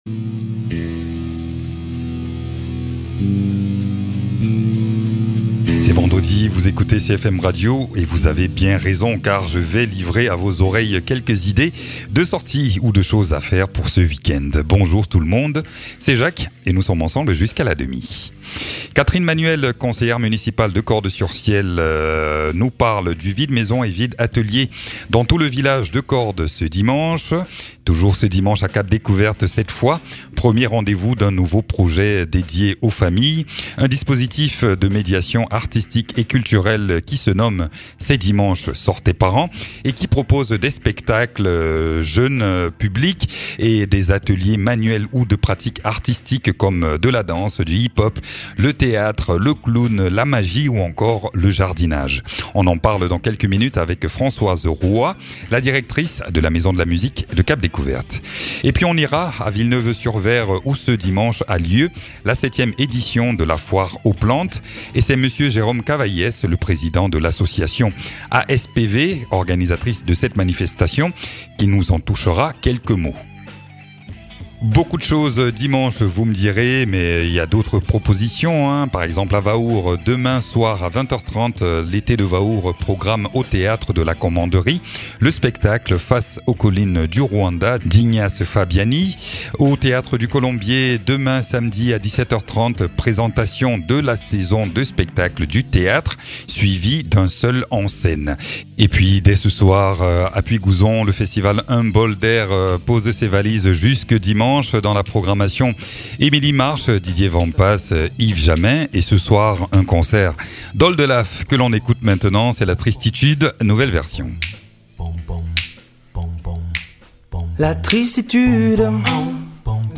Tout ceci en musique et notamment des artistes programmés pour le festival "un bol d’airs" dès ce soir et jusque dimanche à Puygouzon.